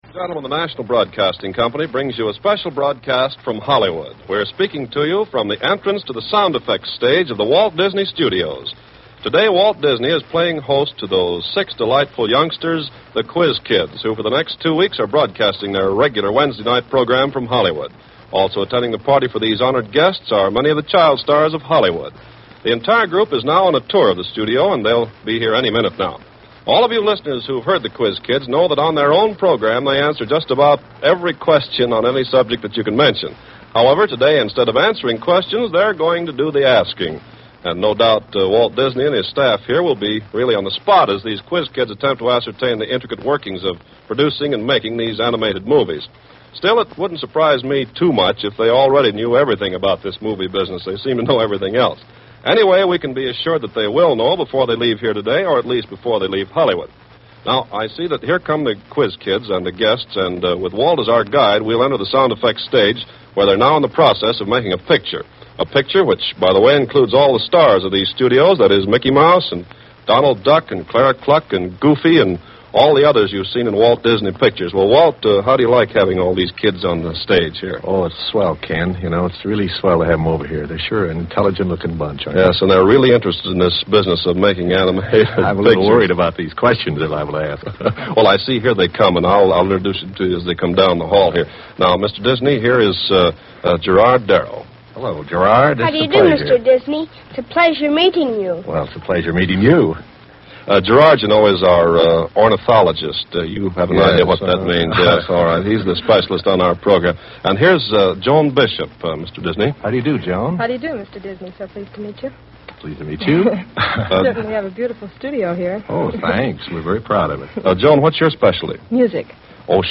Special broadcast from Disney Studios